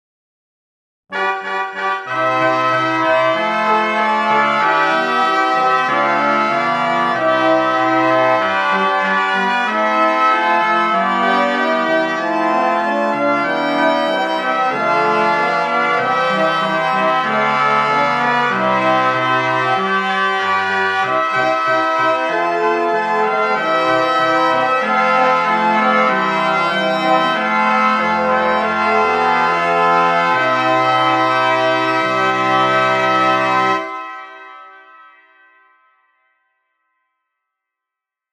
G.Gabrieri　10声(mp3)
音源は、比較のために、全て金管にしています。